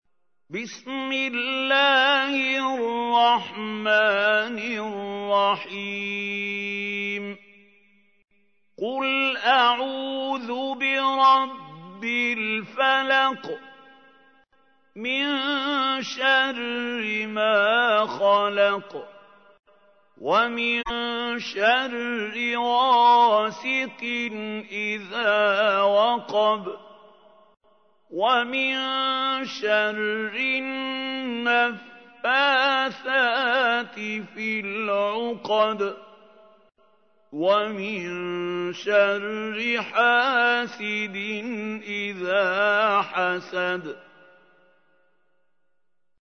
تحميل : 113. سورة الفلق / القارئ محمود خليل الحصري / القرآن الكريم / موقع يا حسين